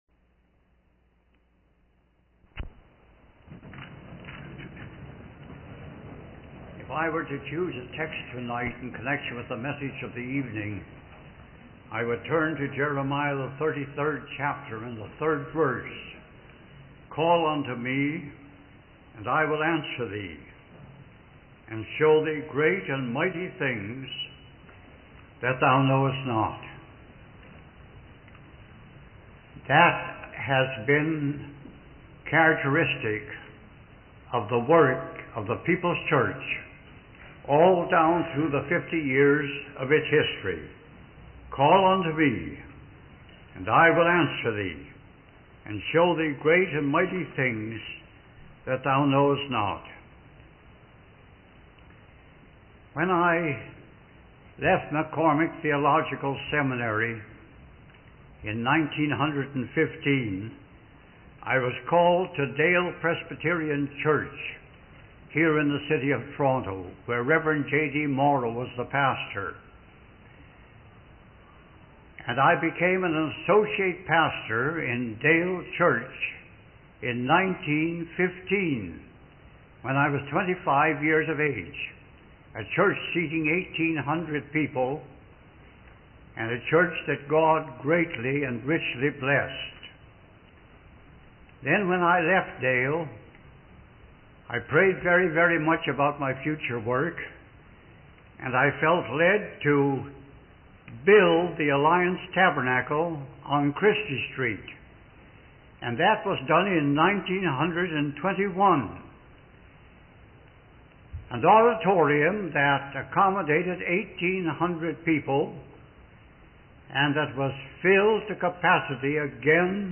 In this sermon, the speaker reflects on the history of the People's Church and their emphasis on evangelism and missions. He shares his personal experience of seeing men and women accepting Jesus Christ as their Savior during the evening services.